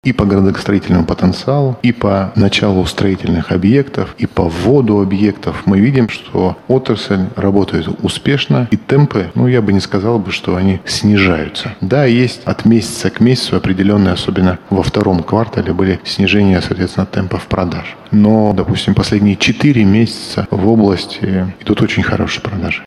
Об этом заявил губернатор Денис Паслер во время прямой линии. По его словам, строительный сектор в регионе демонстрирует хорошие результаты, несмотря на санкции и высокую ключевую ставку.